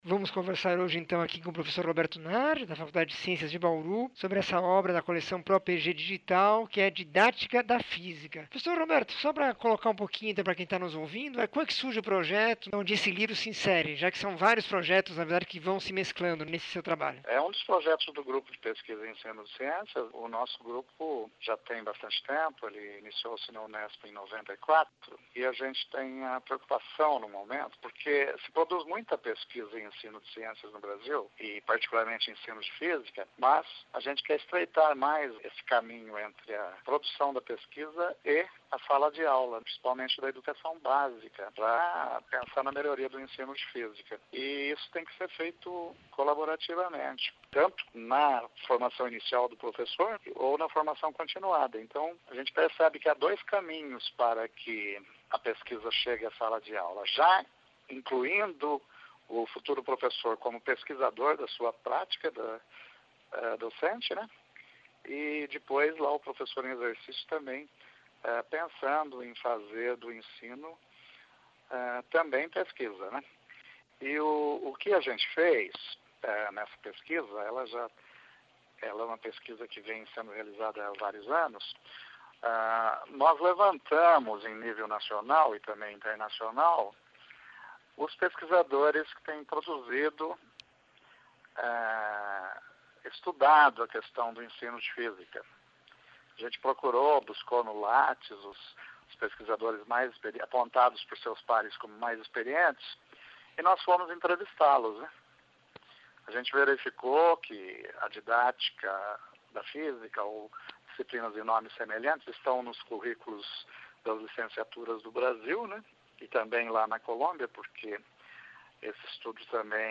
entrevista 2347